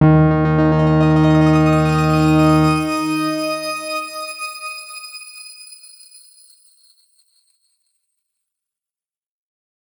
X_Grain-D#2-ff.wav